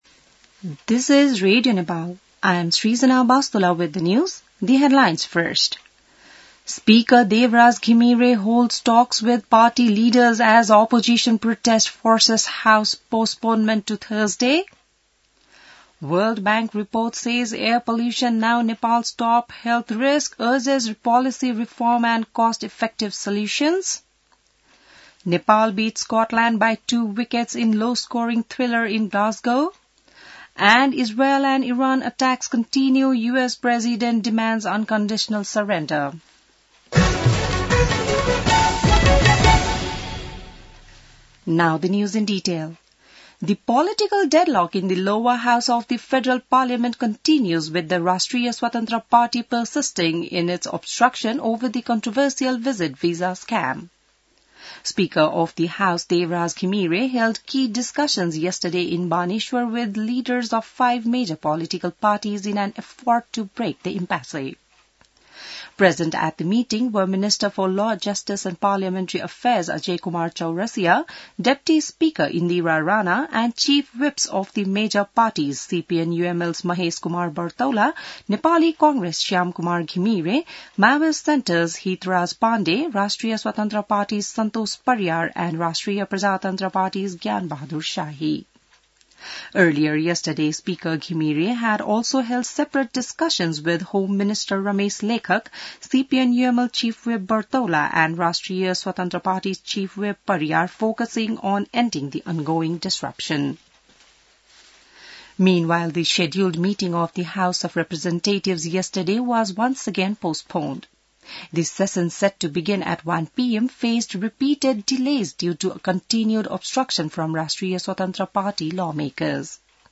बिहान ८ बजेको अङ्ग्रेजी समाचार : ४ असार , २०८२